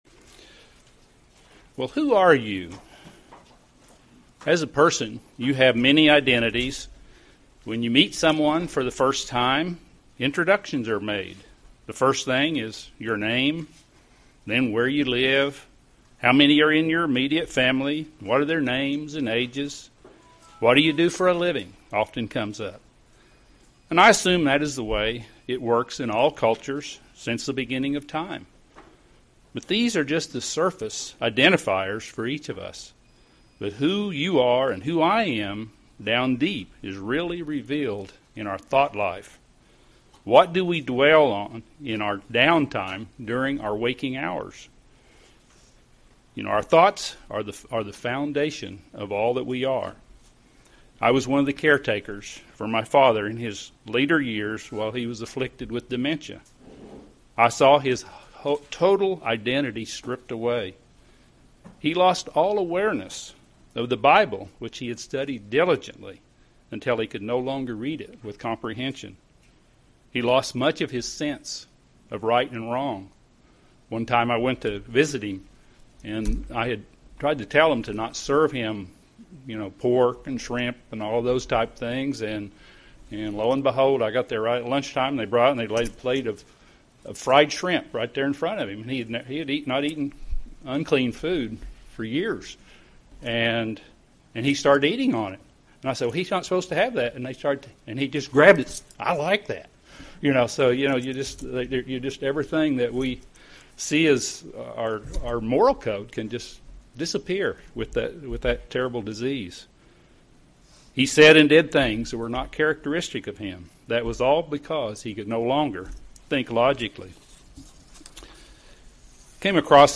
UCG Sermon Notes Who are you?